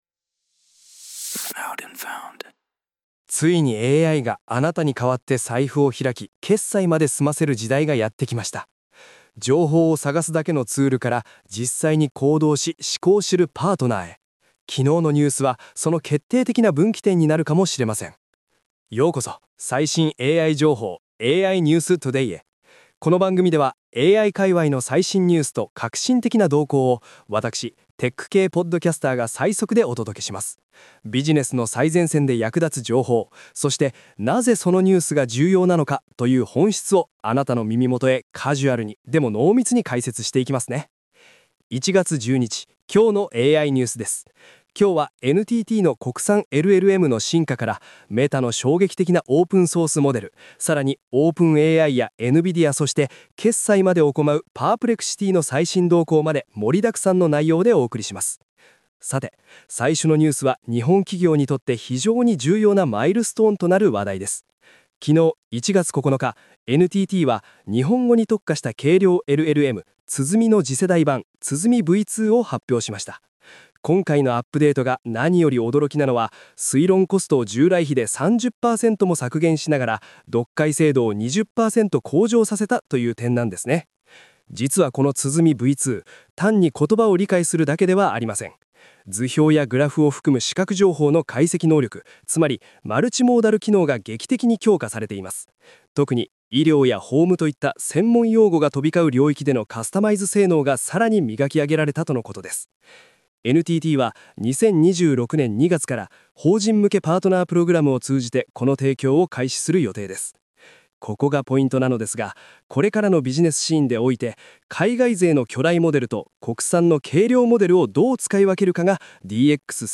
🤖 AI合成音声で最速ニュースをお届け